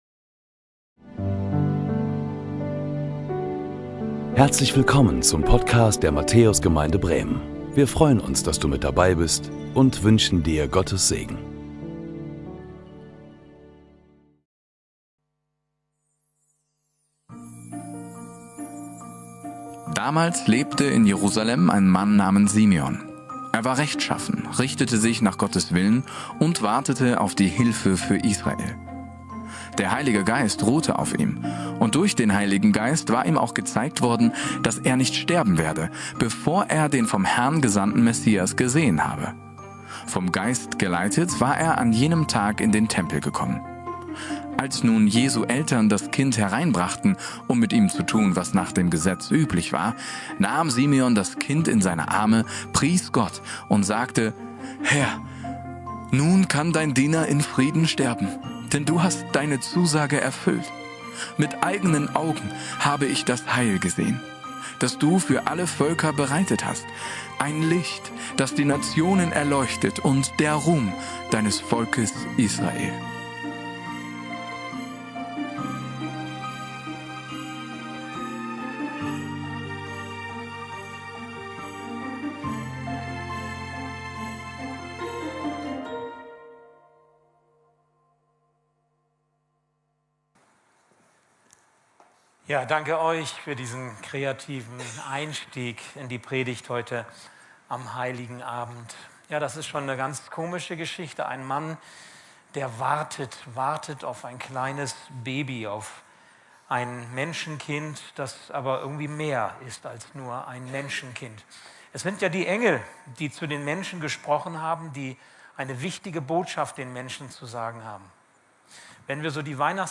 Weihnachtsgottesdienst | Jesus, Licht der Welt – Matthäus-Gemeinde Podcast
Predigten